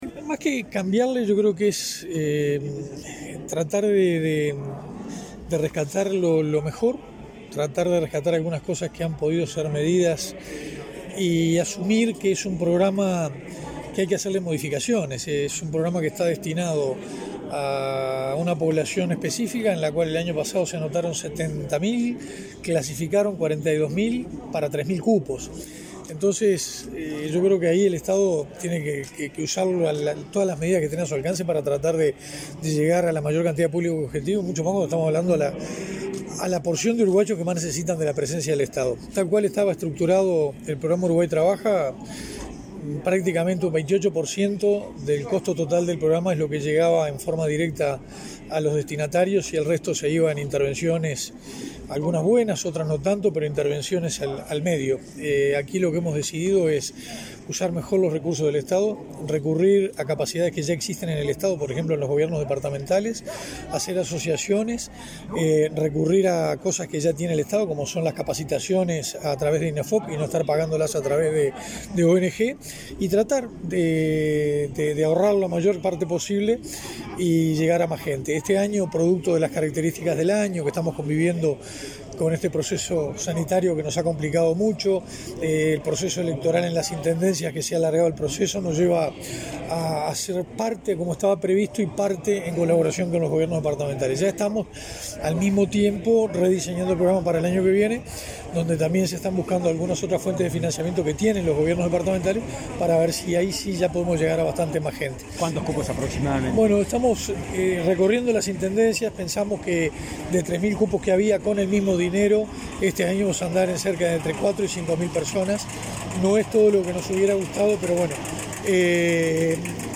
El Ministerio de Desarrollo Social rediseñará Uruguay Trabaja para incrementar la cantidad de participantes y se asociará con los Gobiernos departamentales e Inefop para que el programa beneficie a residentes de lugares donde no llegaba antes, adelantó el subsecretario de la cartera, Armando Castaingdebat. También desarrollará políticas para apoyar a las madres y los jóvenes más vulnerables.